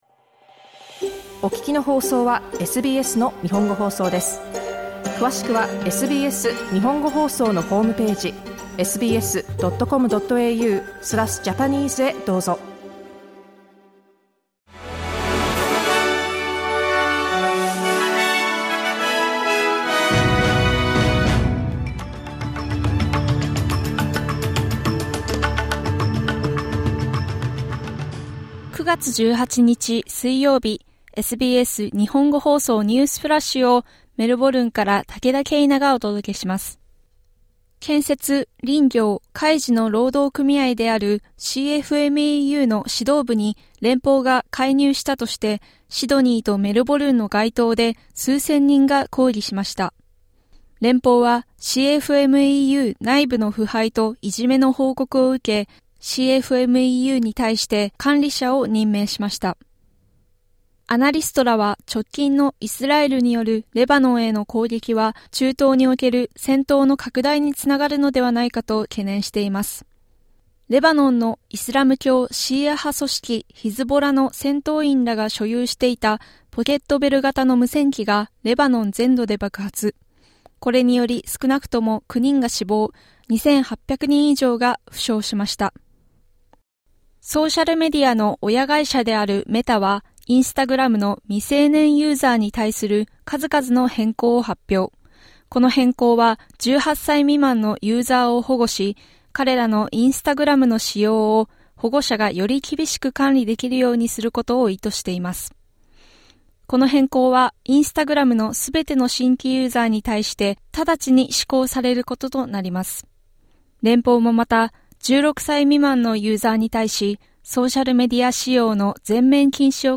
SBS日本語放送ニュースフラッシュ 9月18日水曜日